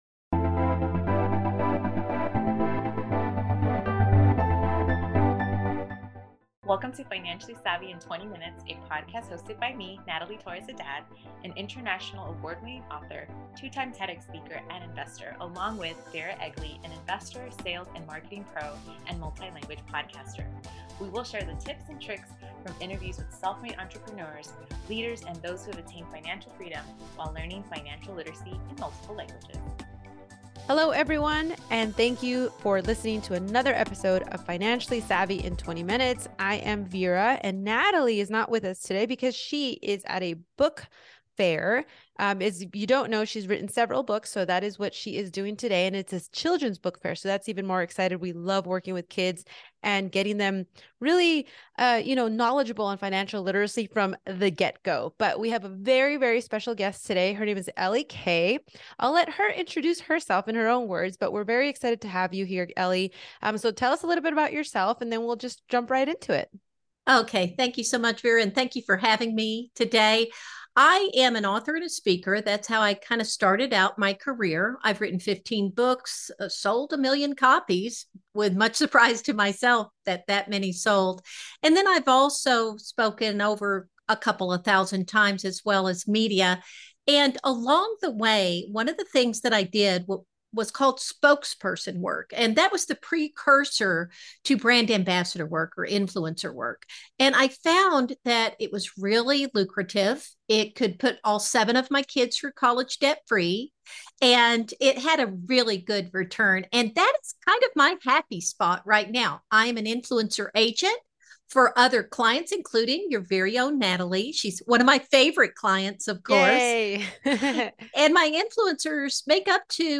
In this episode, we reshare an interview